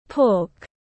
Thịt lợn tiếng anh gọi là pork, phiên âm tiếng anh đọc là /pɔːk/
Pork /pɔːk/